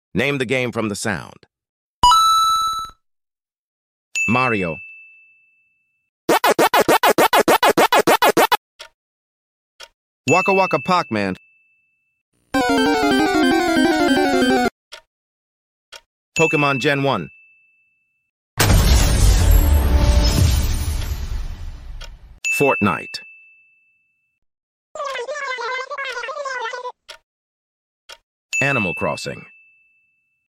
Name the game from the sound effects free download